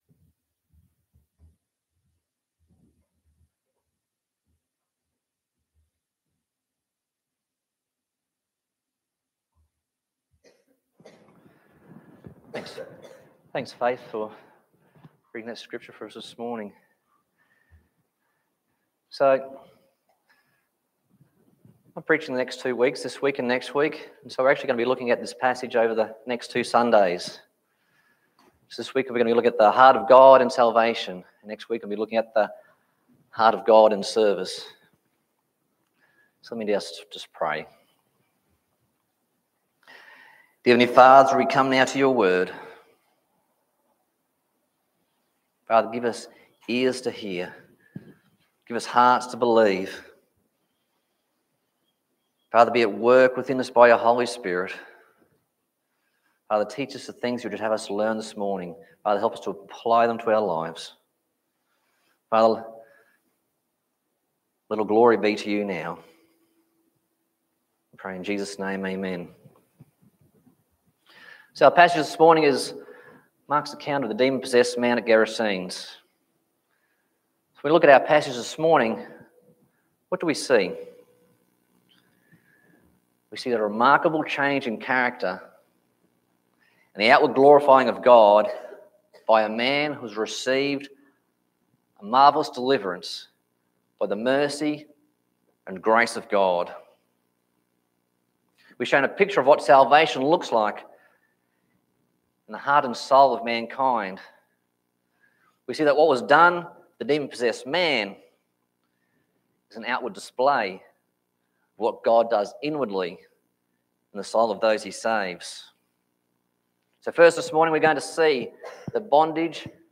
Passage: Mark 5:1-20 Service Type: Sunday Morning